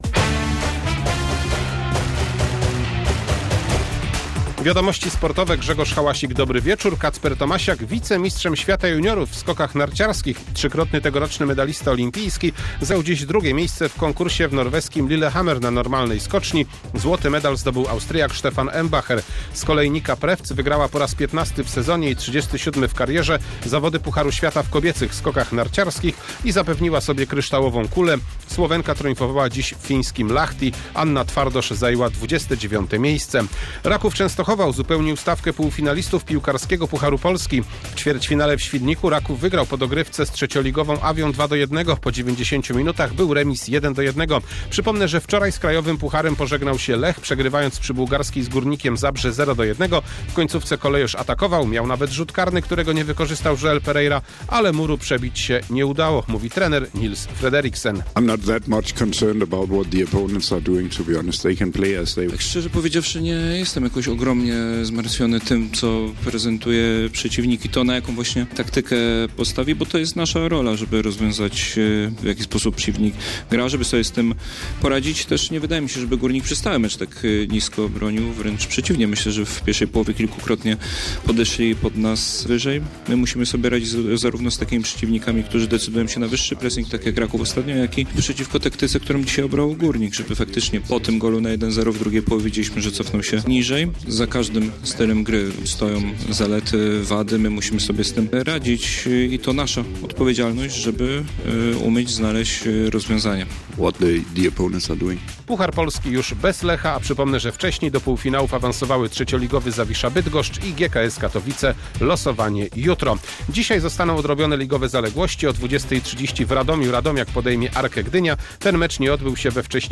05.03.2026 SERWIS SPORTOWY GODZ. 19:05